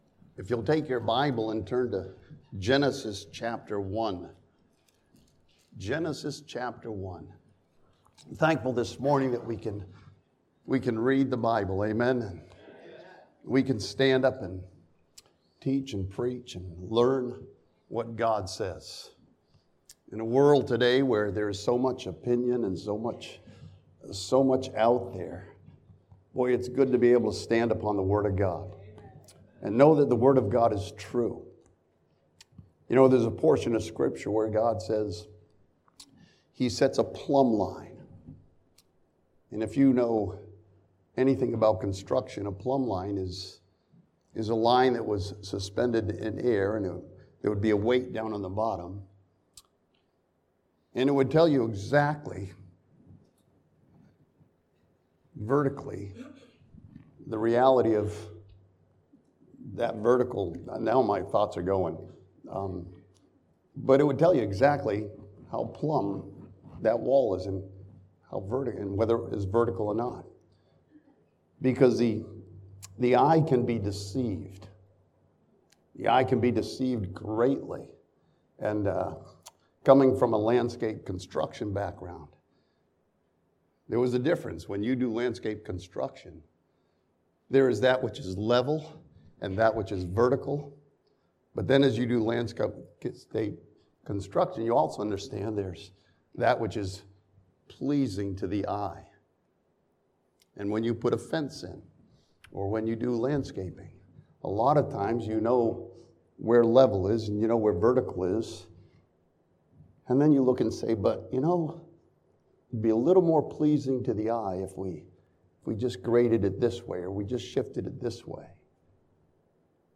This sermon from Genesis chapters one and two studies four important principles from the creation account.